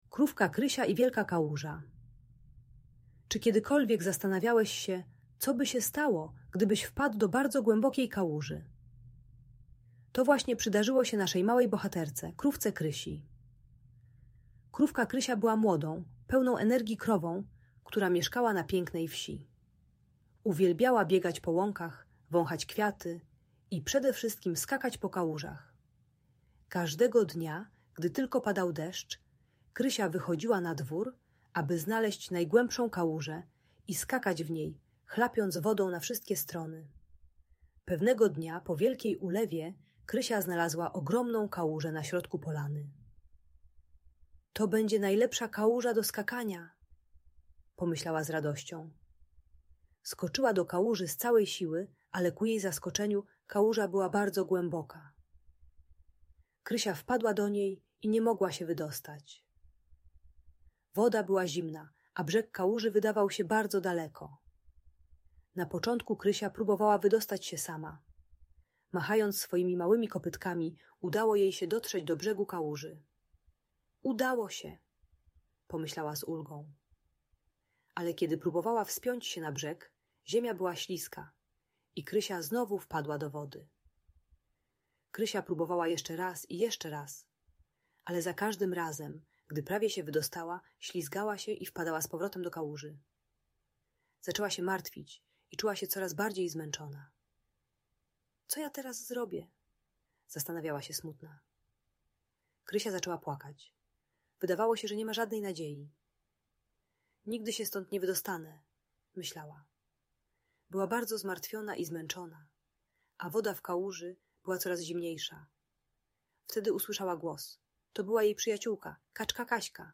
Przygoda Krówki Krysi - Audiobajka dla dzieci